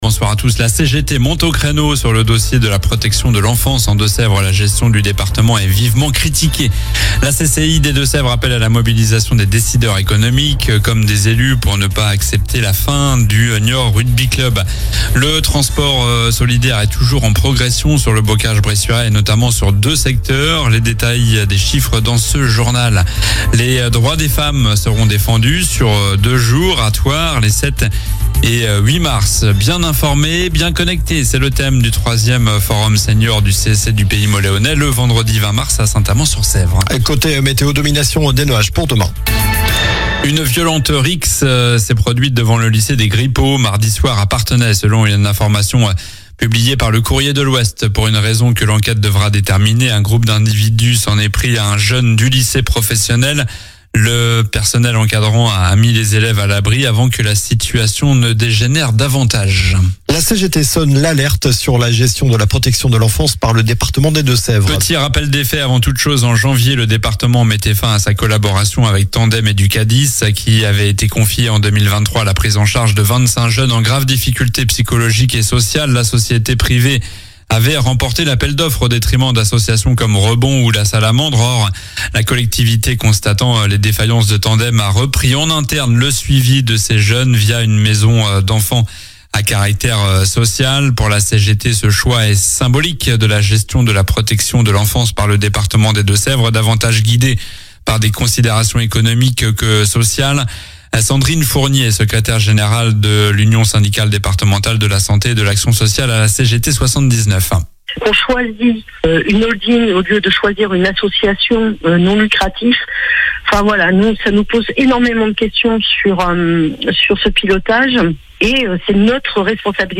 Journal du jeudi 05 mars (soir)